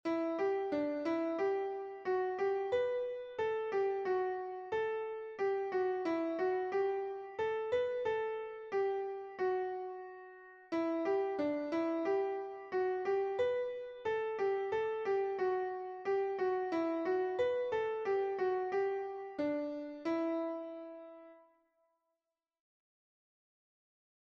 It's a slow melody in E minor, 4/4 time.